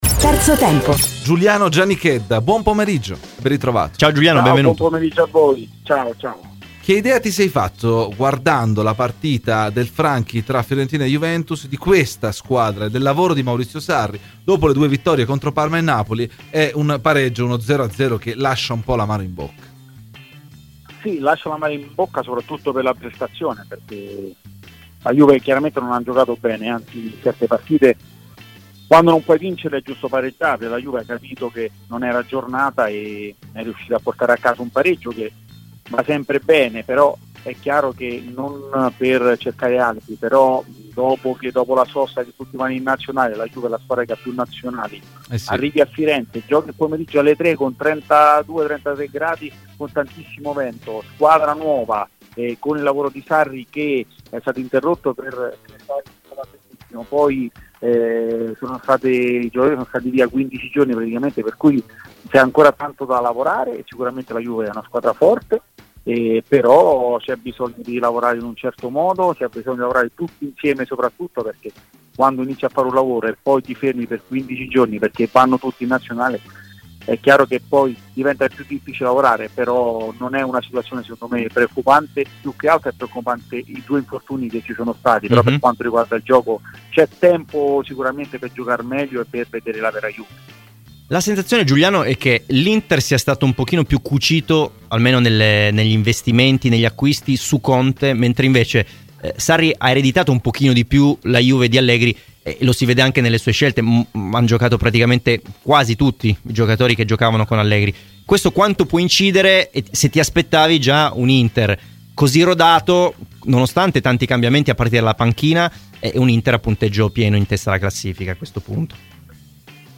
Ai microfoni di Radio Bianconera, nel corso di ‘Terzo Tempo’, è intervenuto l’ex Juventus Giuliano Giannichedda: “Fiorentina-Juventus? È un pareggio che lascia l’amaro in bocca soprattutto per la prestazione, la Juventus non ha giocato bene.
Clicca sul podcast in calce per l’intervento completo dell’ex calciatore.